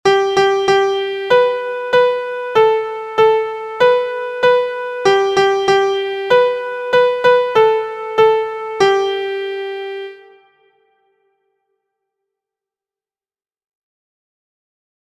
• Origin: Spiritual
• Key: G Major
• Time: 2/4
• Form: ABaB verse/refrain